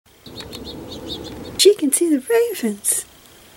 Throughout much of the video there will be a clip of Songbird speak followed by my attempt to imitate the Songbird accent while speaking what seem to me to be the words they just spoke.